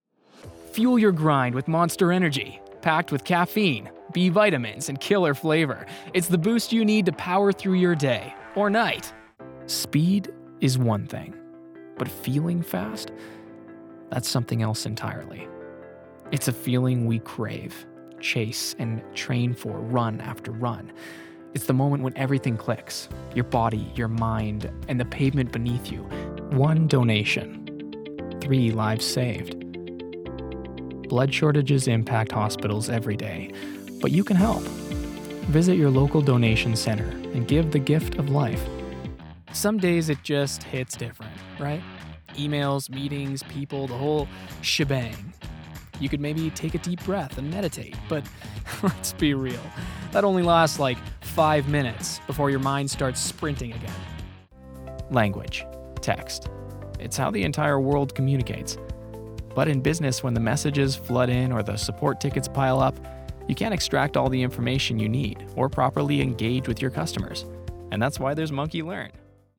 Professional male voice over talent based out of Vancouver, Canada.
Commercial Demo
English - USA and Canada
Young Adult